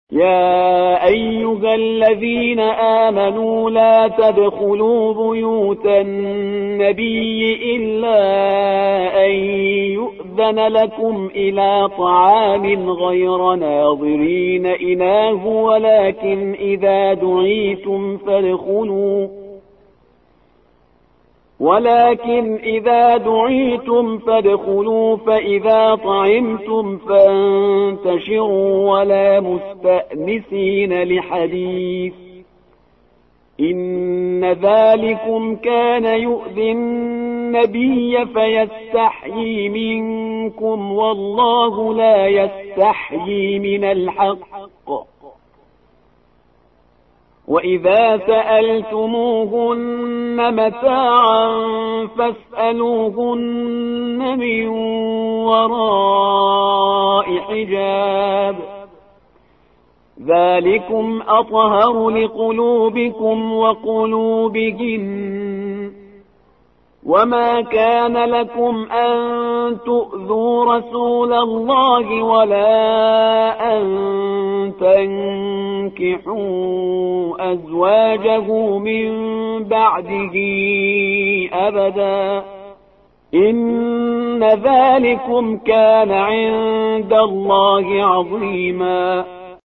تلاوت آیه ۵۳ سوره احزاب